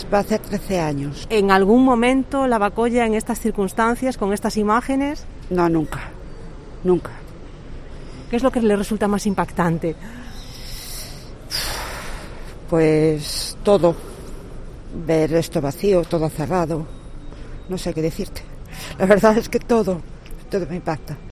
En una terminal casi vacía la megafonía recuerda a los viajeros que deben guardar la distancia de seguridad por motivos sanitarios: una trabajadora del aeropuerto nos explica que hoy aun es de los mejores días, hay gente... porque están programados cuatro llegadas y tres salidas.